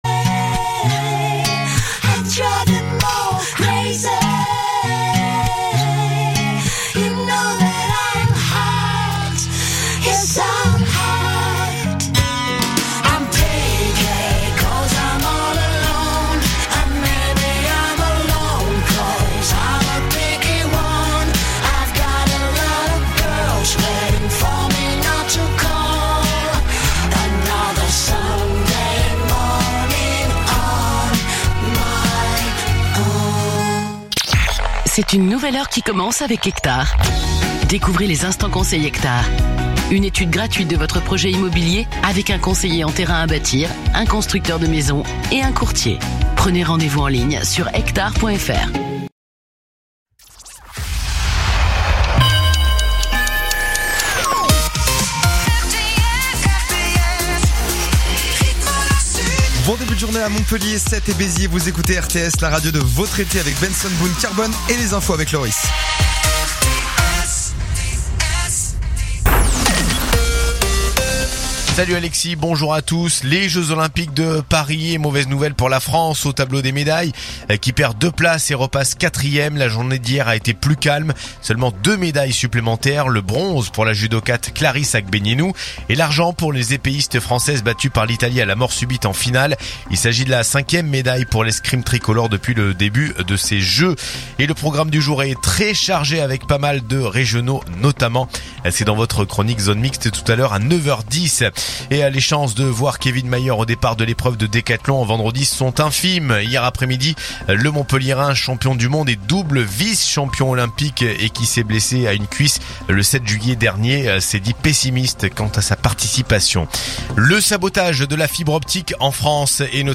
info_narbonne_toulouse_96.mp3